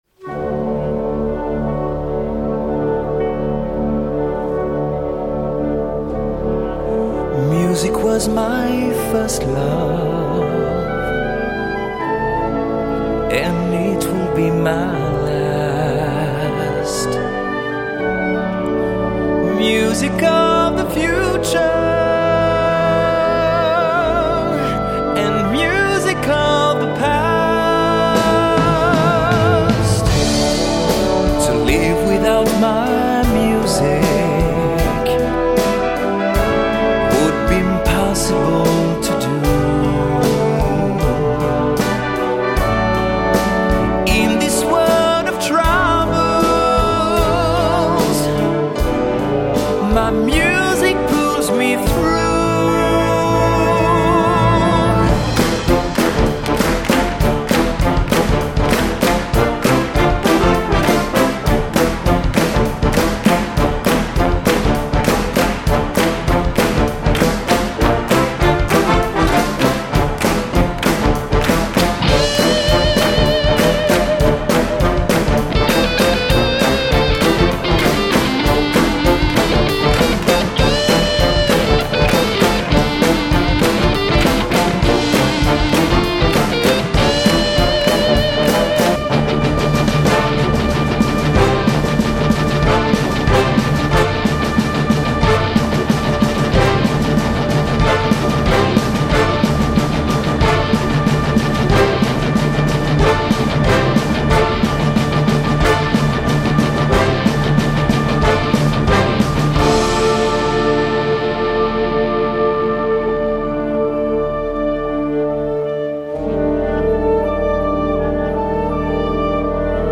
Gattung: Solo Voice mit Blasorchester
Besetzung: Blasorchester